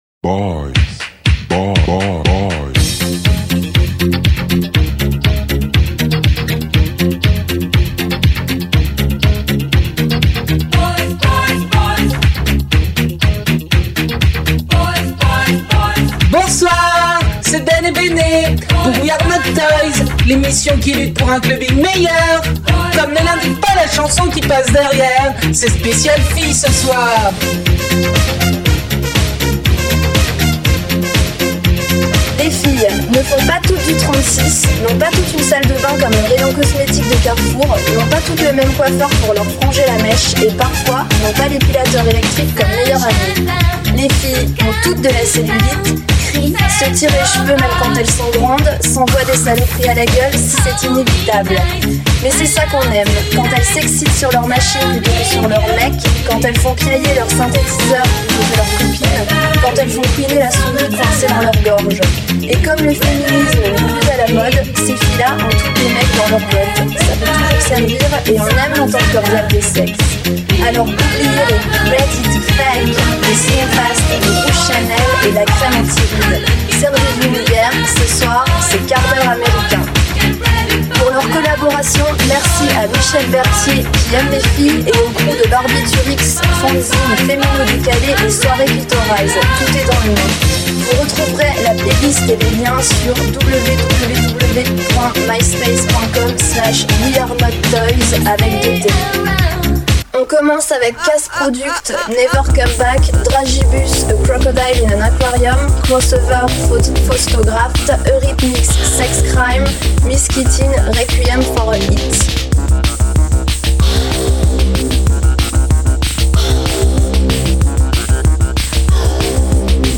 Amateurs de musiques electronique !
8bit, ecletique